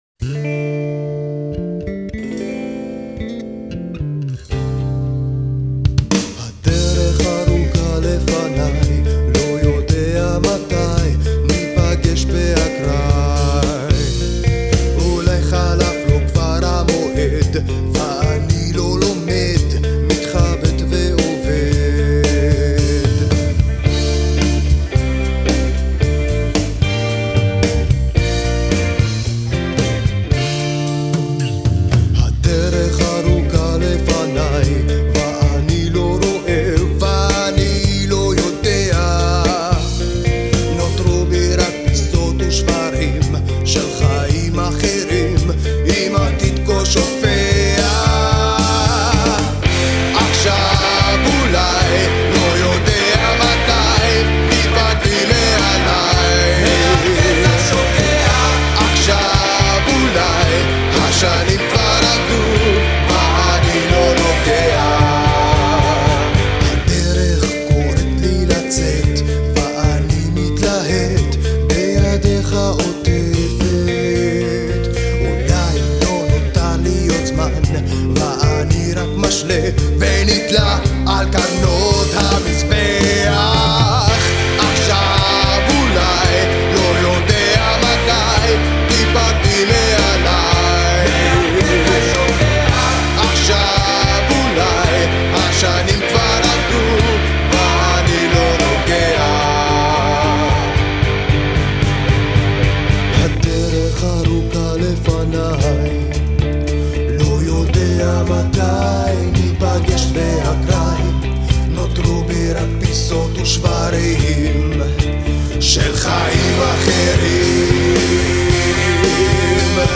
הרכב רוק בן ארבעה חברים
תופים
גיטרות וליווי קולי
בס וליווי קולי
שירה